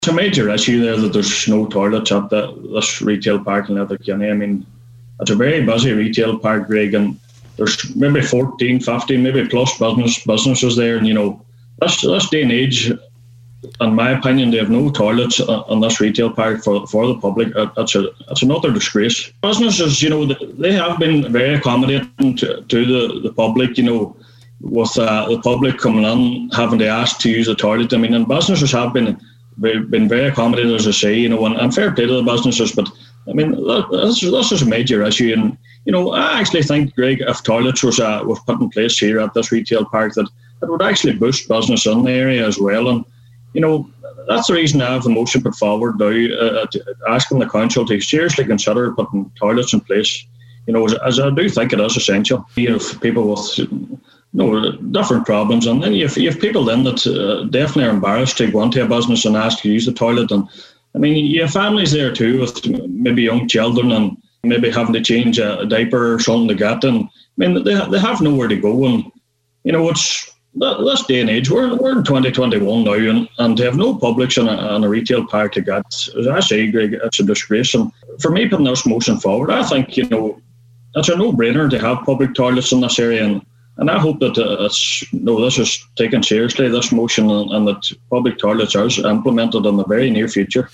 Cllr Kelly told today’s Nine til Noon Show that while businesses have been accommodating, it’s time he says, the Council stepped up to the mark: